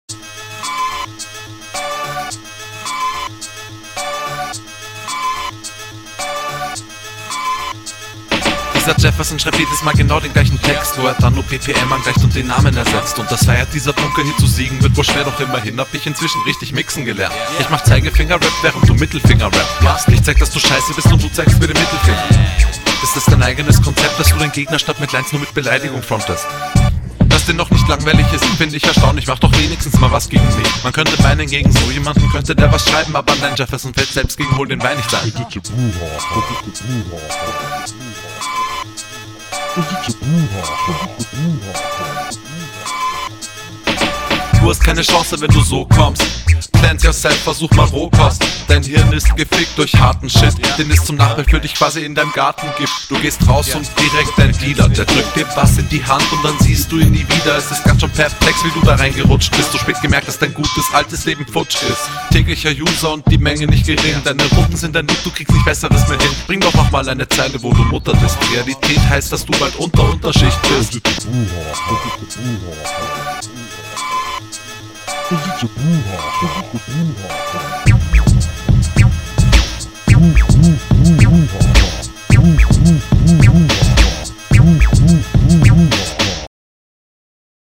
Flow: Flowlich ganz ok. Bist hier mal etwas aus der Komfortzone raus.